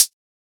Closed Hats
edm-hihat-49.wav